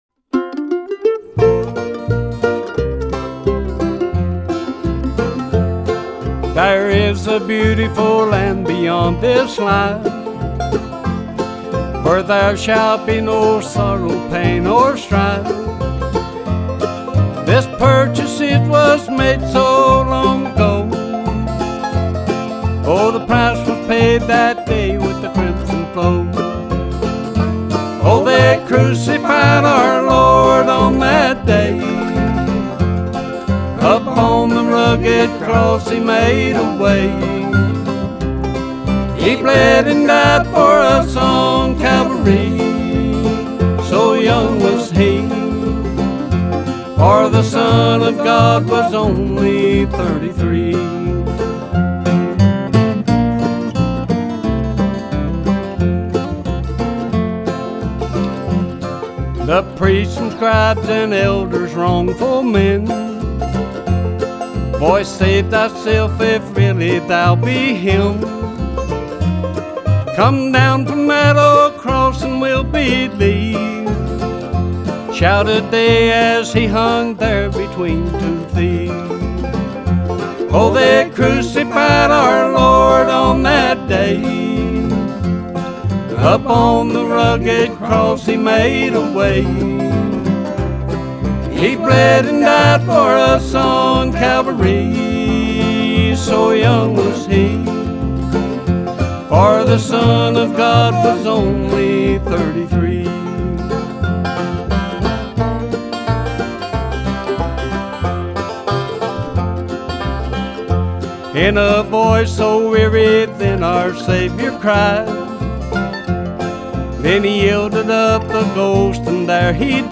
This is American traditional music at its very best.